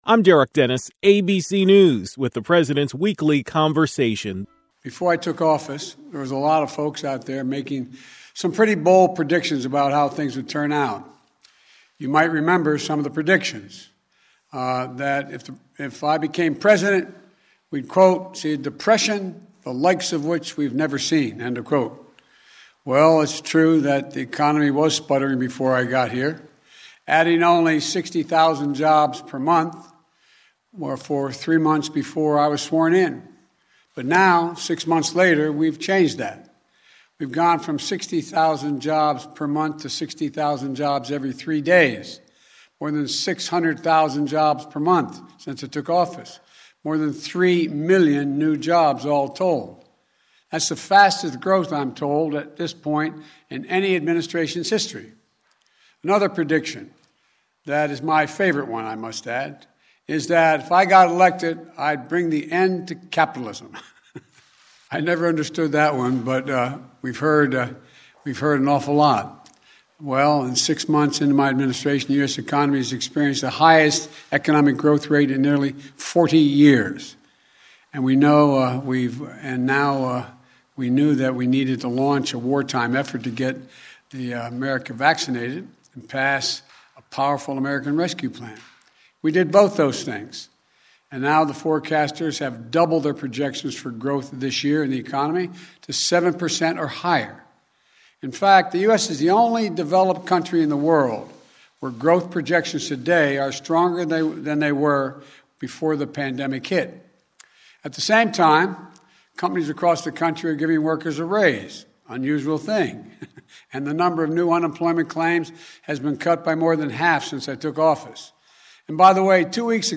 President Biden delivered a speech on the state of the economy.